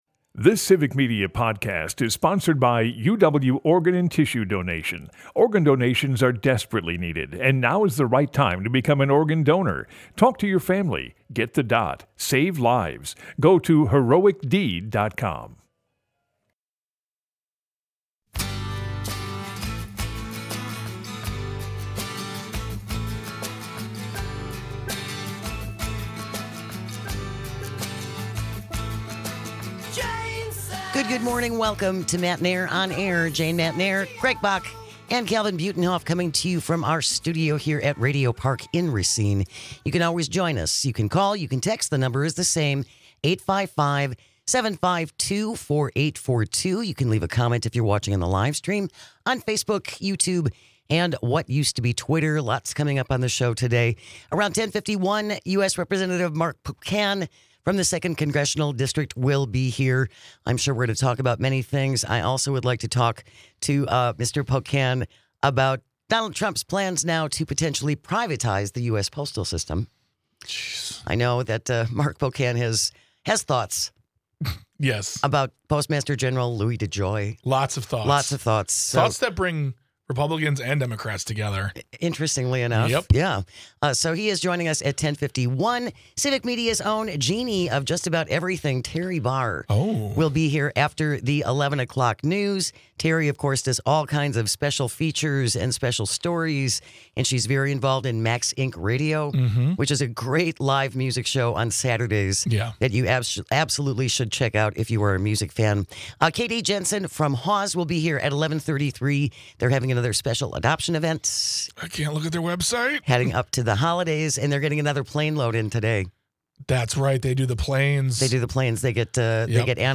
They cover all the news that affects you with humor and a unique perspective. From ginseng farmers to state politicians to bird enthusiasts and more.